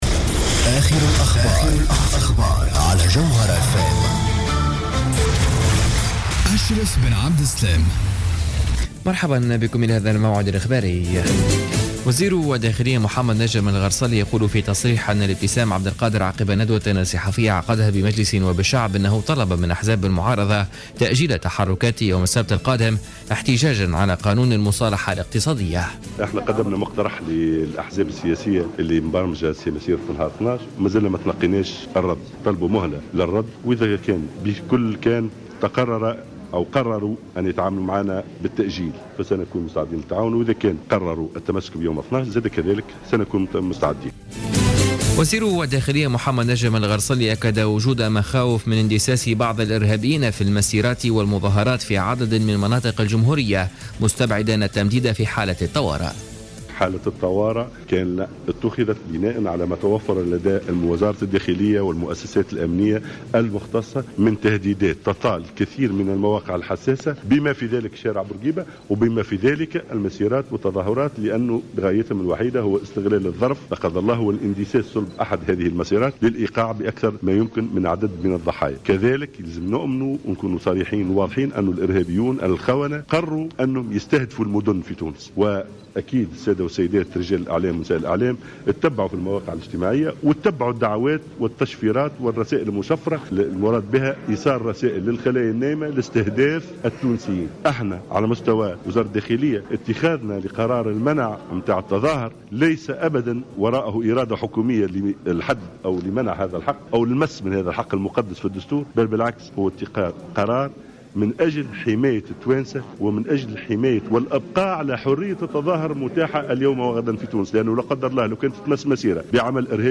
نشرة أخبار منتصف الليل ليوم الجمعة 11 سبتمبر 2015